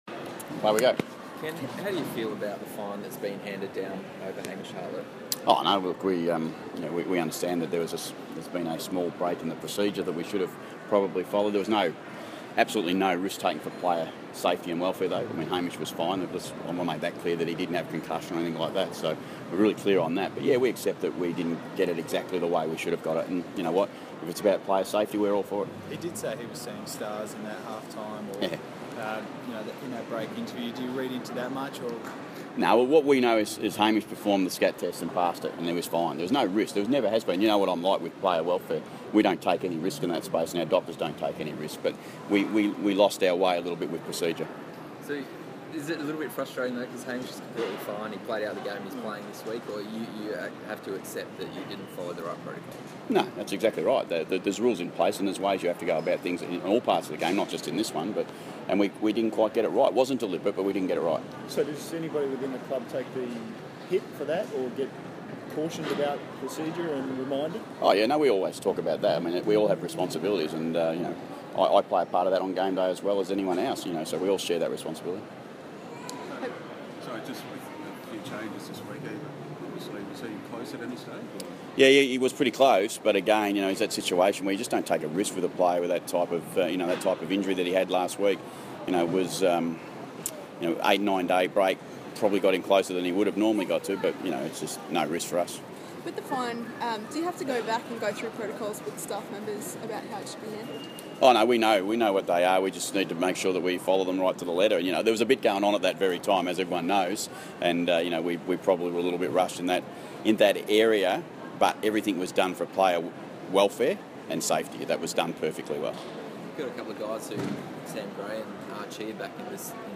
Ken Hinkley Press Conference - Friday, 15 July, 2016
Ken Hinkley talks with media before Port Adelaide flies to Melbourne to play North Melbourne at Etihad Stadium.